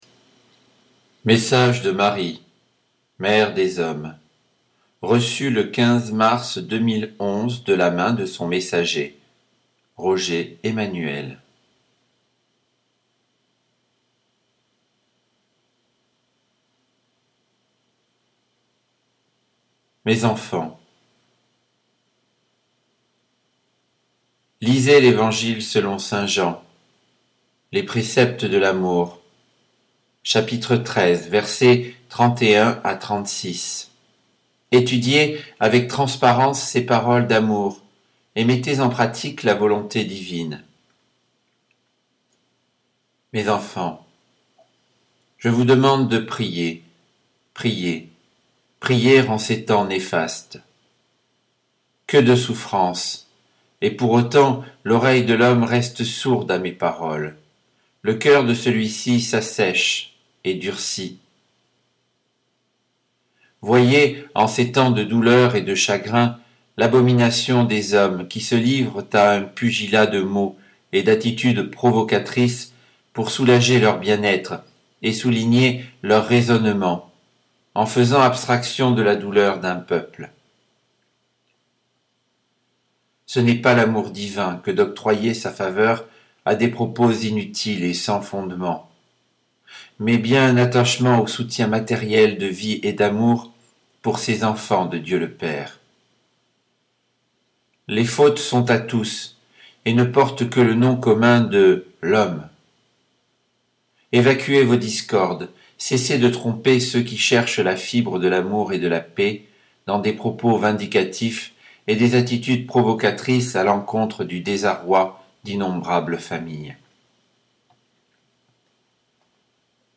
Lecture du message du 15 mars 2011 (version MP3)